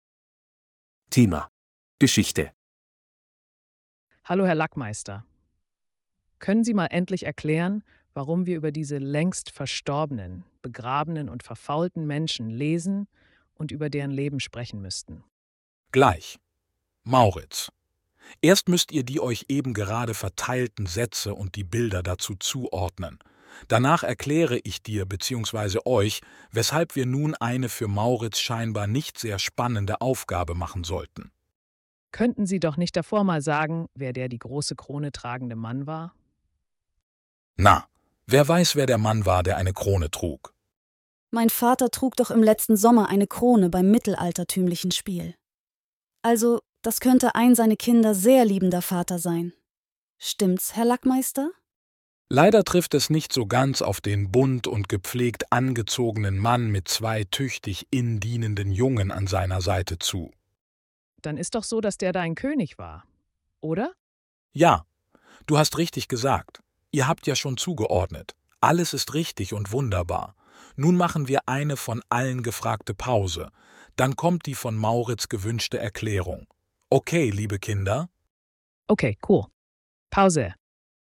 Hörtext für die Dialoge bei Aufgabe 4